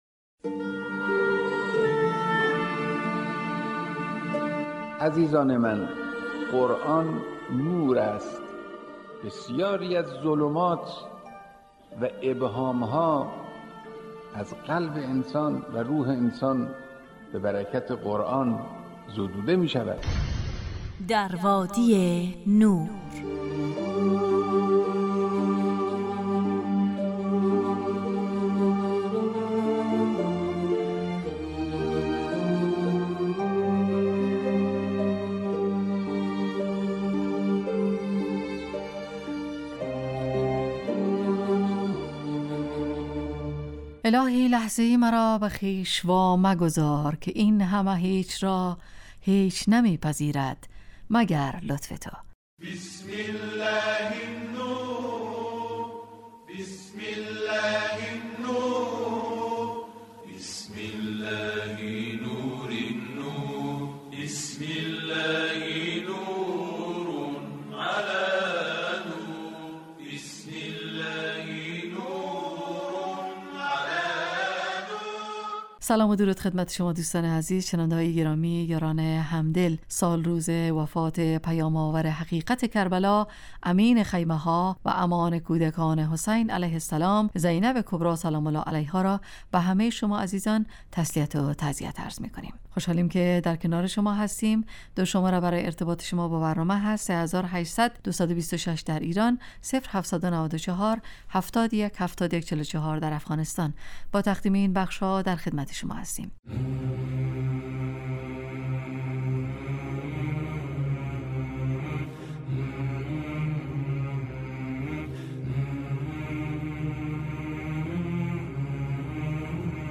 ایستگاه تلاوت